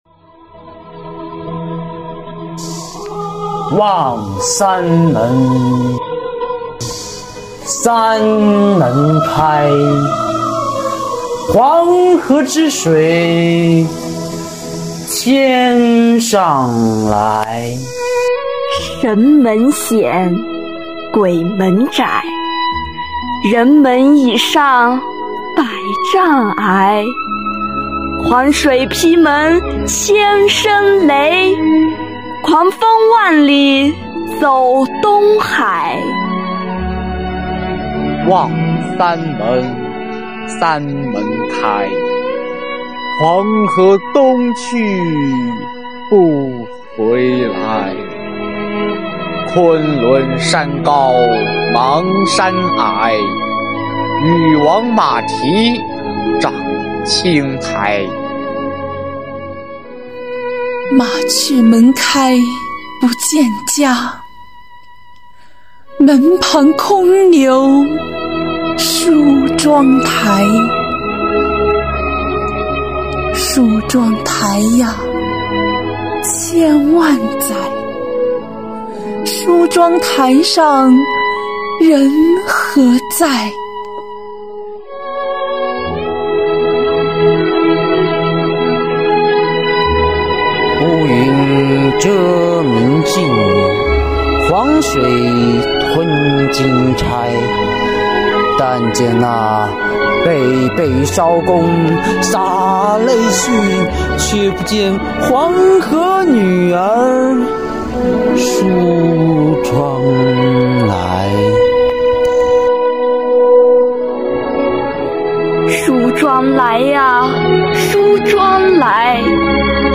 朗诵者们精神饱满，一字一句流露真情实意。
【优秀朗诵之三：《三门峡 梳妆台》】